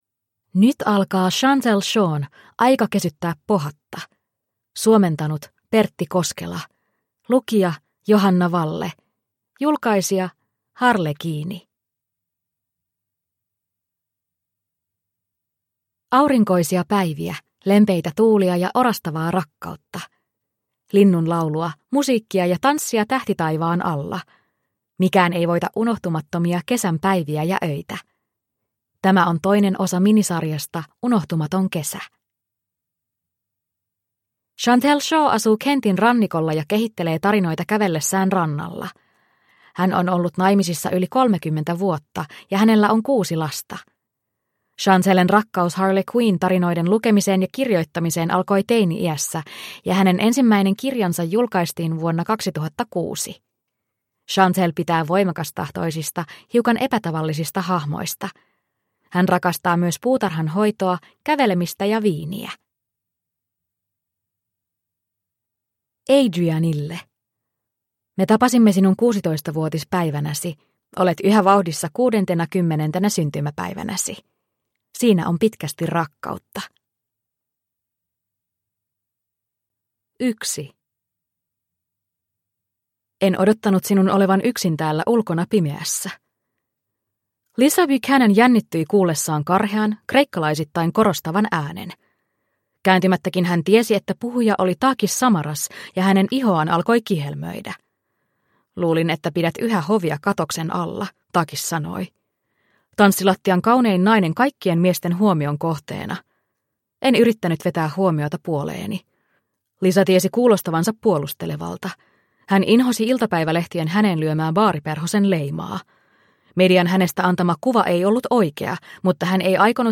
Aika kesyttää pohatta (ljudbok) av Chantelle Shaw